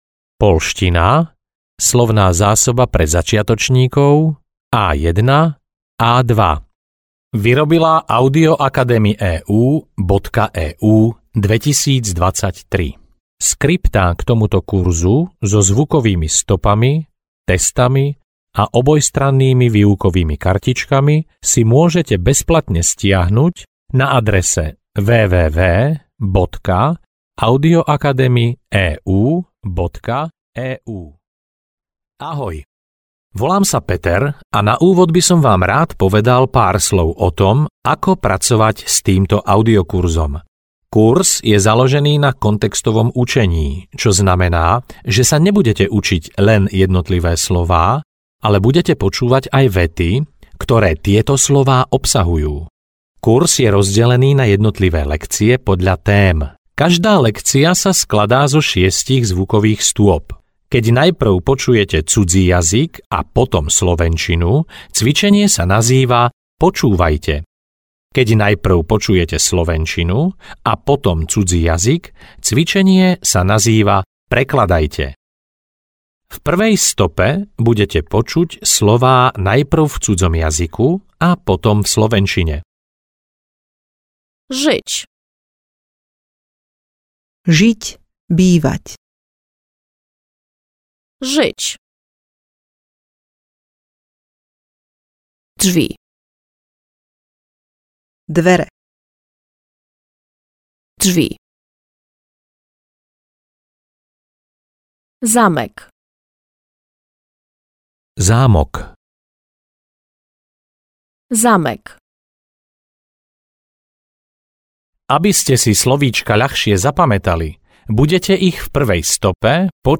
Audio knihaPoľština pre začiatočníkov A1-A2